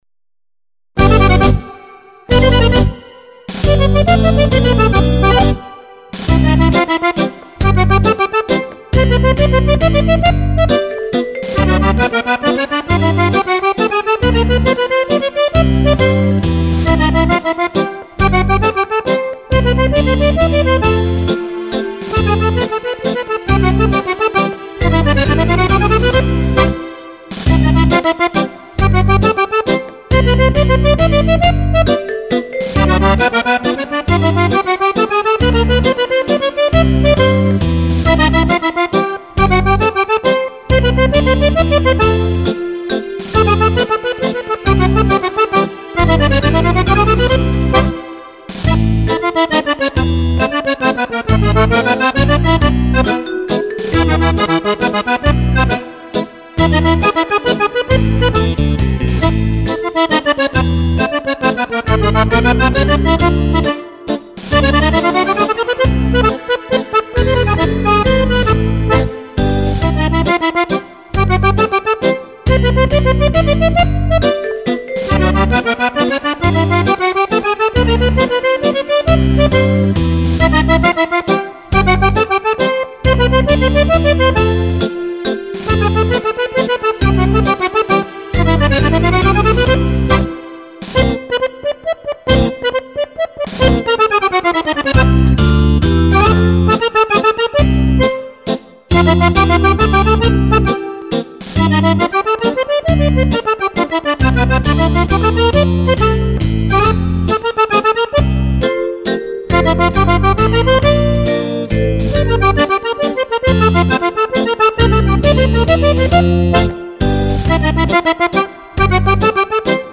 (Mazurka per Fisarmonica).